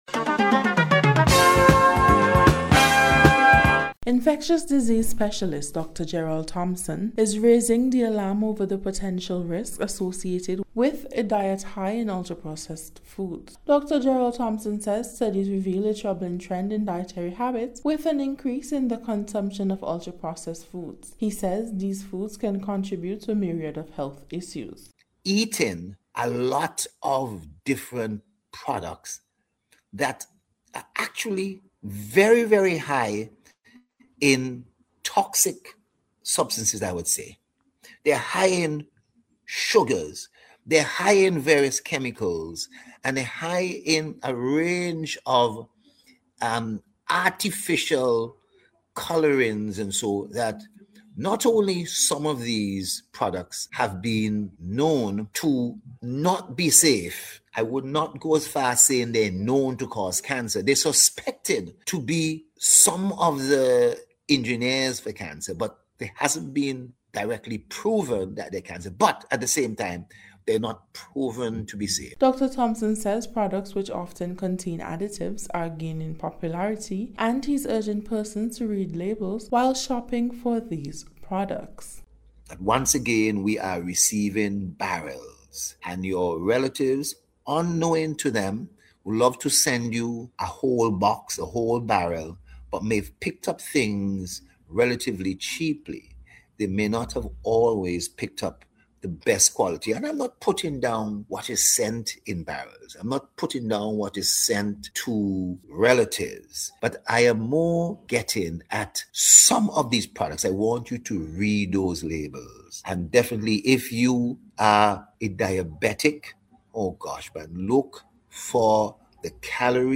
ULTRA-PROCESSED-FOODS-REPORT.mp3